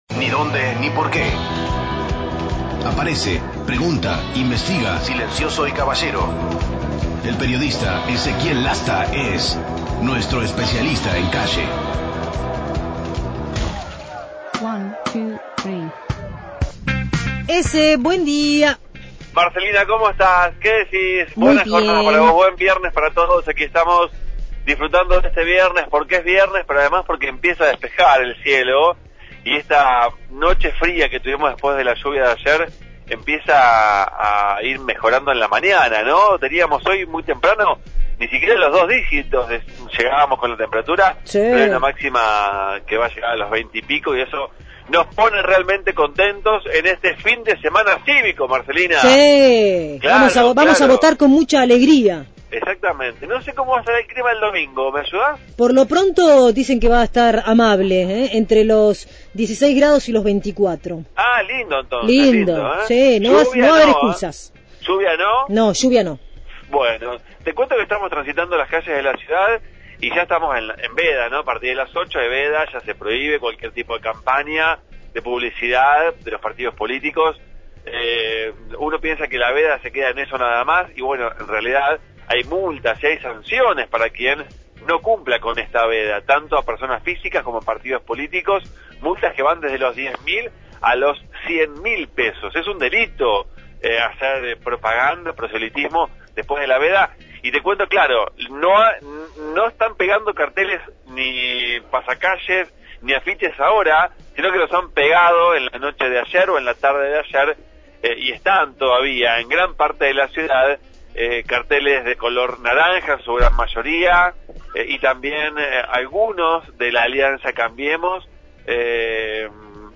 MÓVIL/ Feria de puesteros del Mercado en 520 y 19 – Radio Universidad